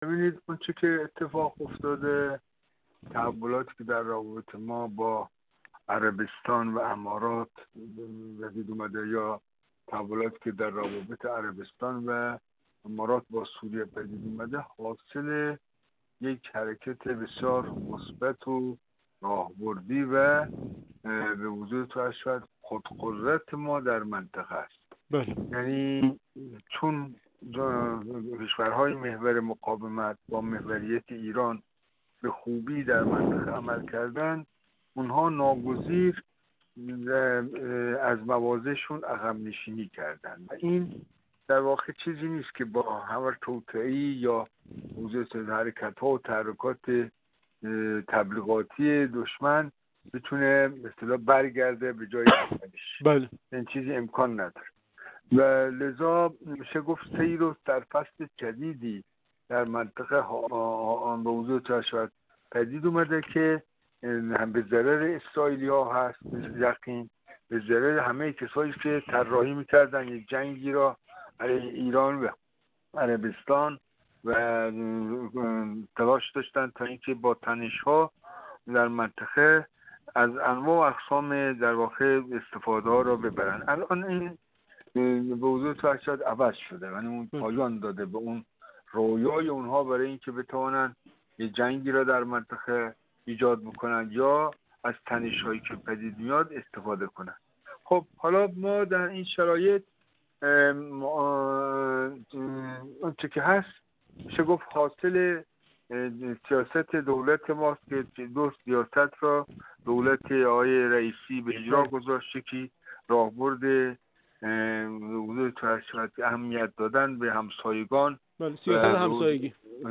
کارشناس مسائل غرب آسیا
گفت‌وگو